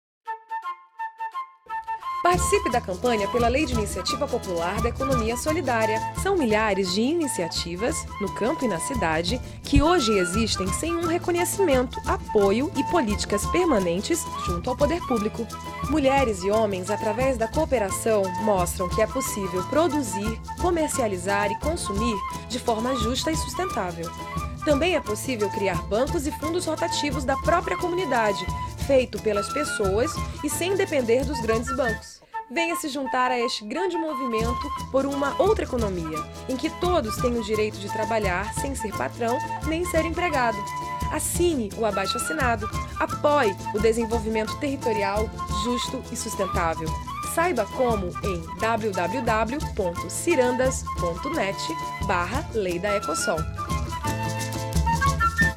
Campanha Leidaecosol Spot De Radio Mp3